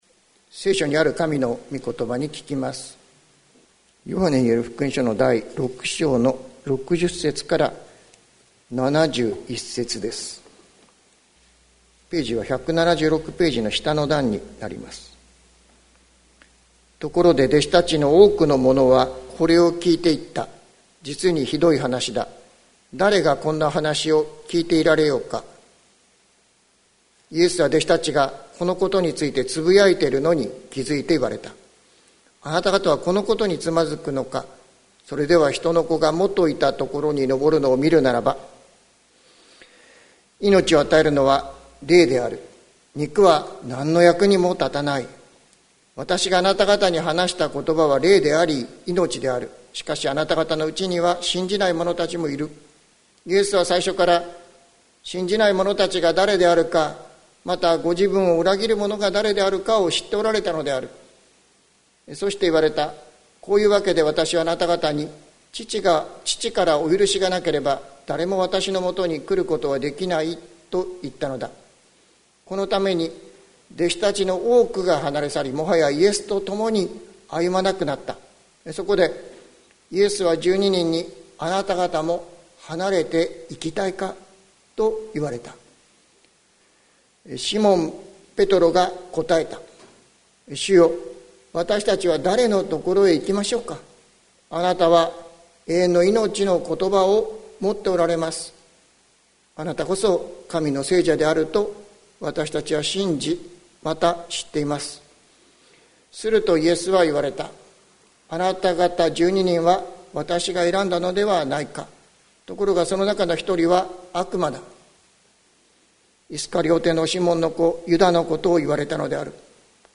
2022年05月29日朝の礼拝「まことの信仰はつまずきの先にある」関キリスト教会
説教アーカイブ。